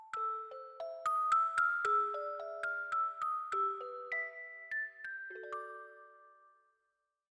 ジングル